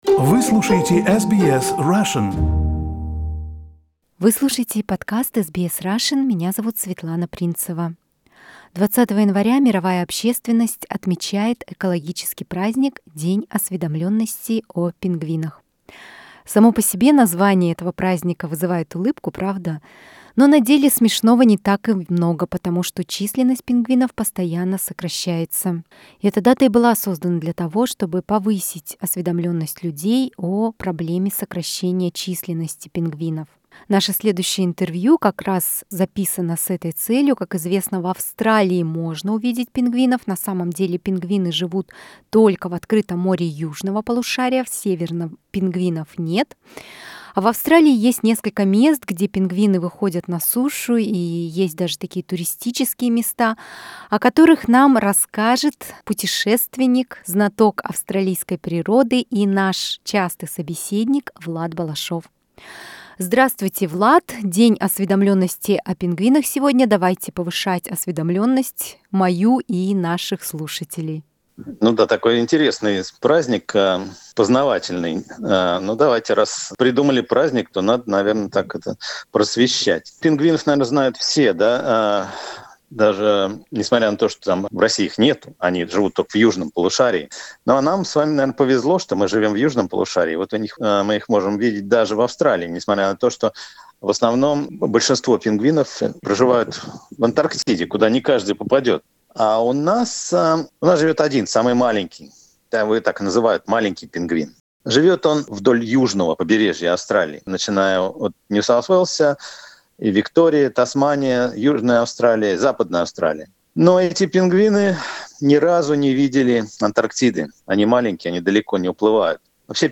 Пингвины в Австралии. Беседа